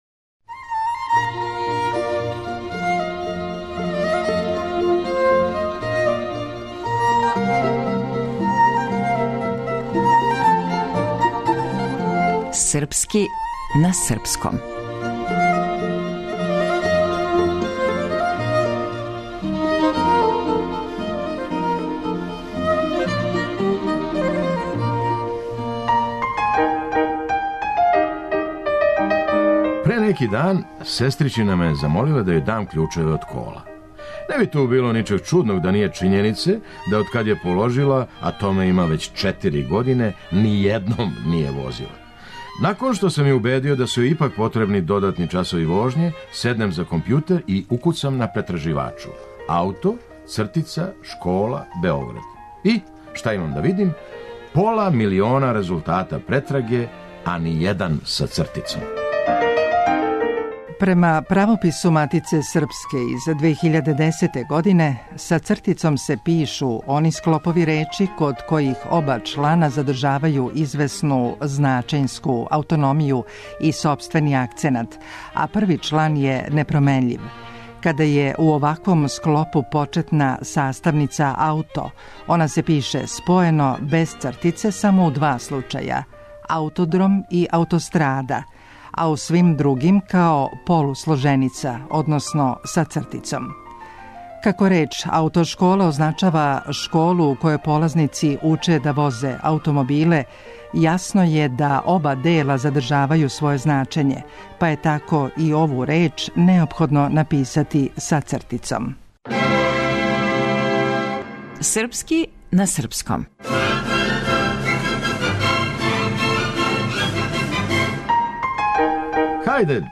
Драмски уметник: Феђа Стојановић